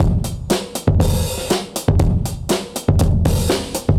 Index of /musicradar/dusty-funk-samples/Beats/120bpm/Alt Sound
DF_BeatA[dustier]_120-04.wav